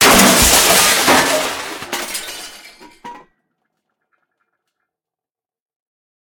CosmicRageSounds / ogg / general / combat / armor / break.ogg